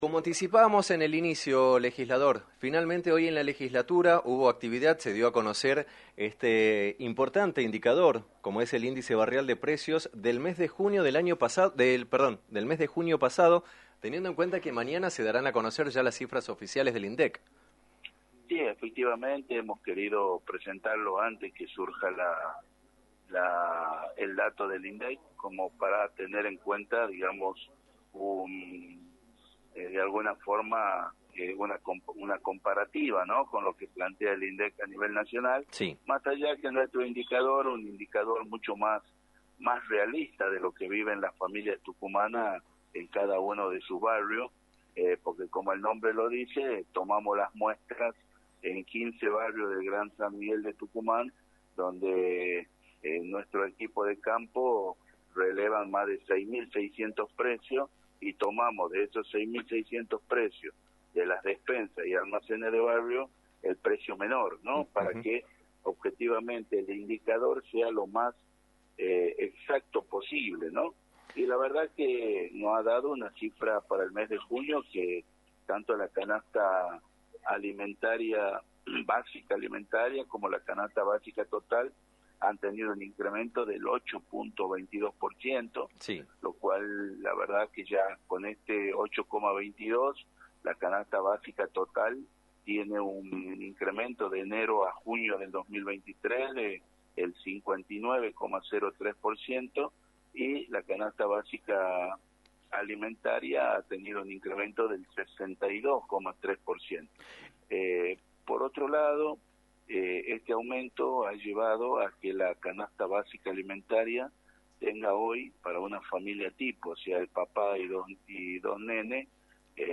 En diálogo con el programa «Dos a la Tarde» por la Rock&Pop 106. 9, el legislador Federico Masso del Movimiento Libres del Sur, expresó su preocupación por las graves consecuencias del proceso inflacionario que atraviesa Argentina.